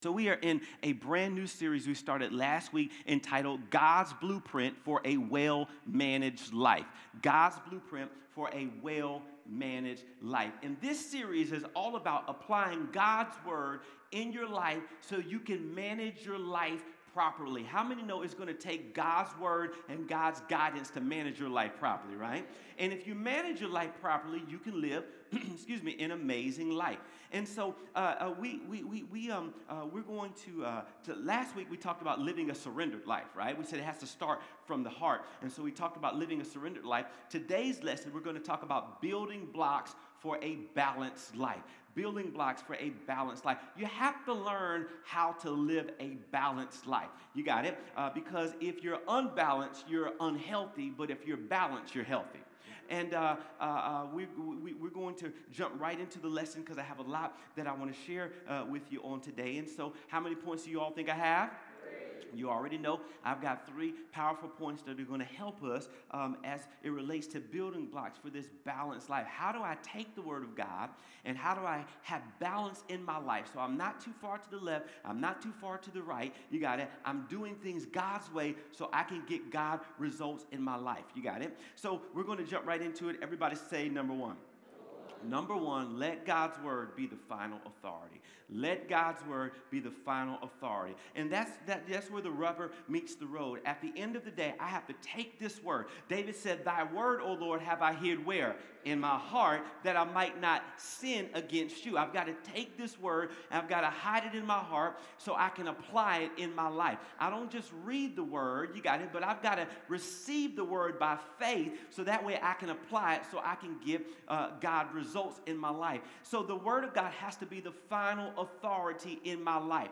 Connect Groups Events Watch Church Online Sermons Give Building Blocks For A Balanced Life September 14, 2025